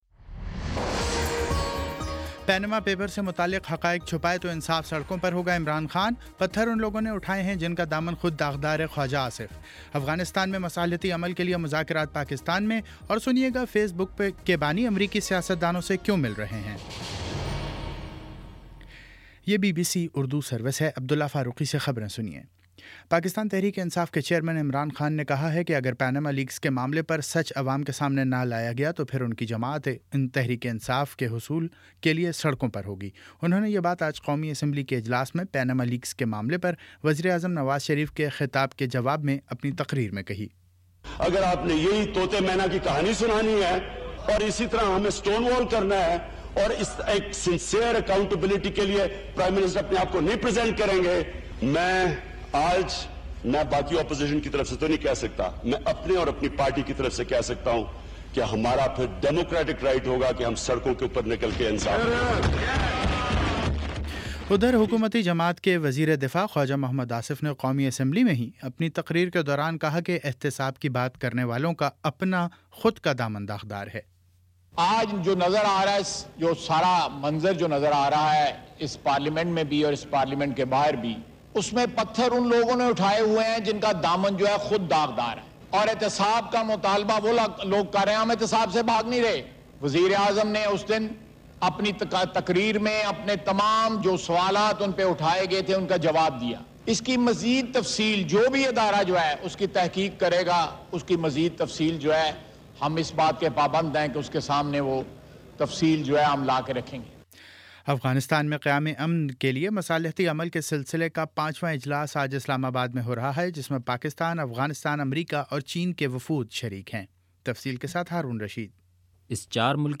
مئی 18 : شام پانچ بجے کا نیوز بُلیٹن